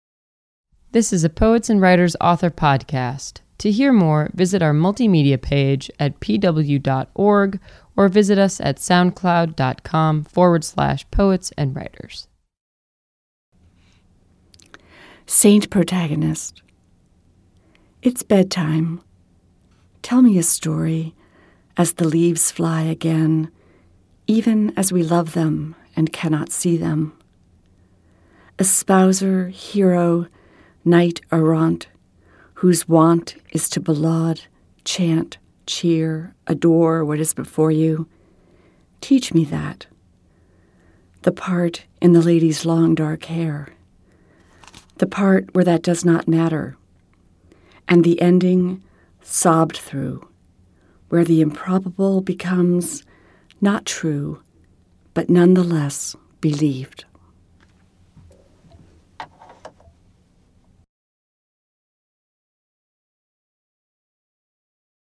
audio | poetry